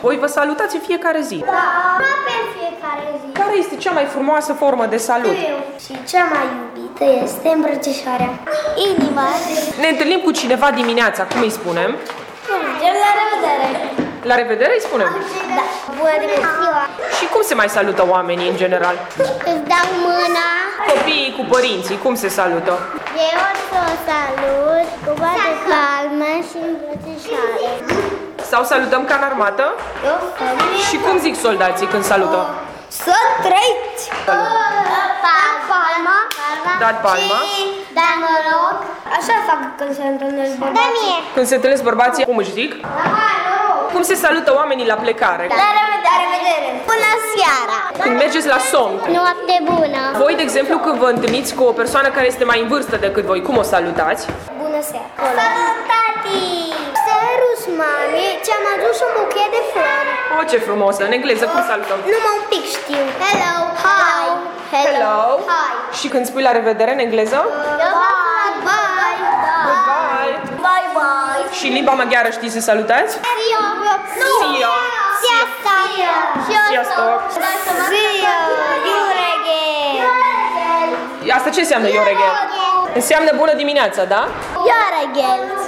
Preșcolarii din grupa mare a Albinuțelor, de la grădinița „Dumbrava minunată” povestesc despre formele de salut pe care le cunosc. Copiii au învățat că este important să fim amabili unii cu altii și că un simplu „bună ziua” ne poate aduce multă bucurie. Dintre toate formele de salut cunoscute, preșcolarii preferă îmbrățișarea.